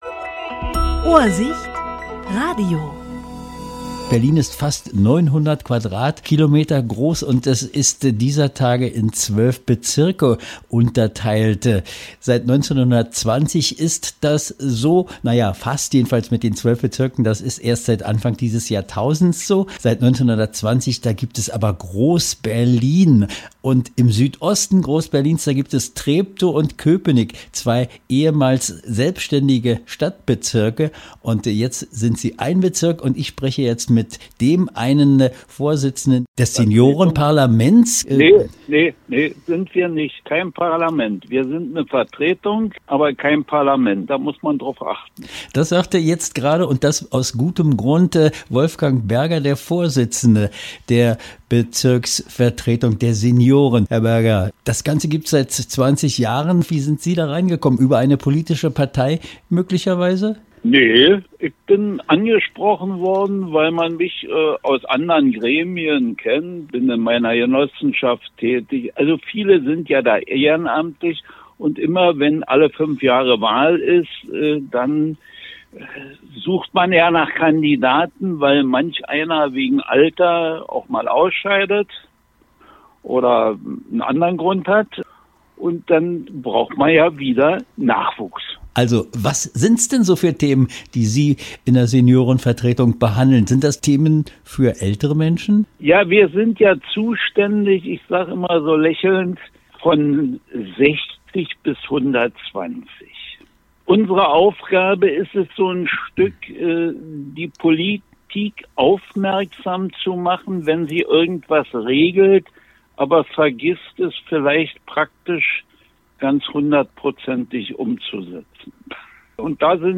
Interview 18.02.2026